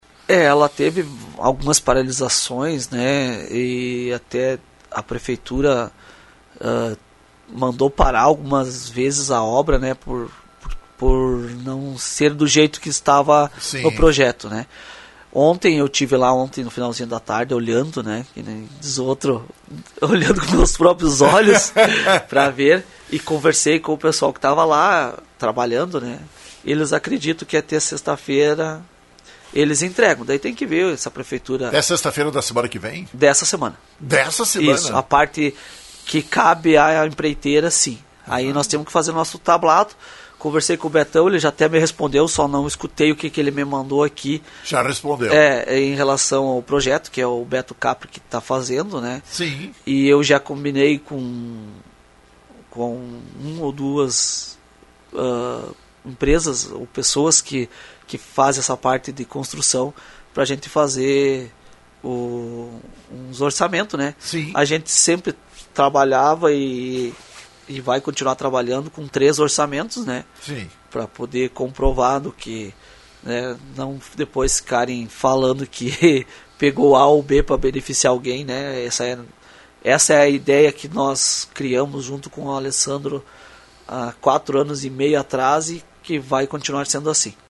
na manhã desta terça-feira em entrevista à Rádio Lagoa FM.